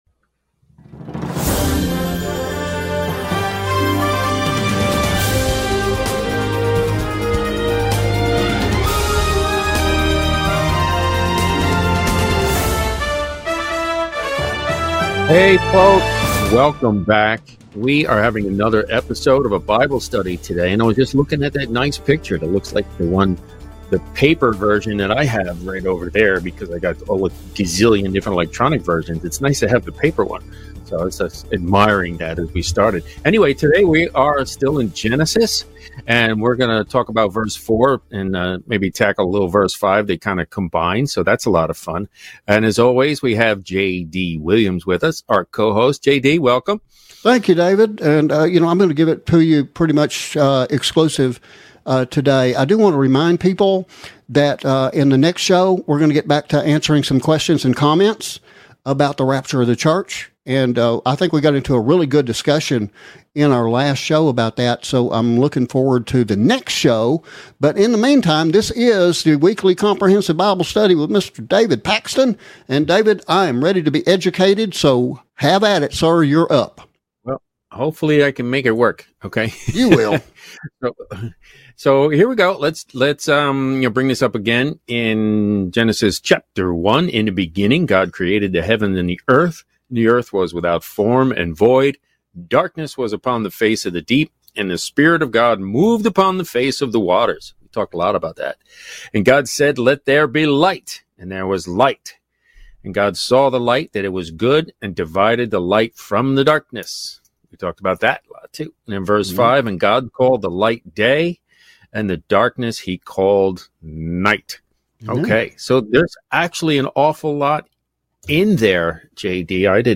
Weekly Comprehensive Bible Study - Exploring the Earth, Stars and Time Itself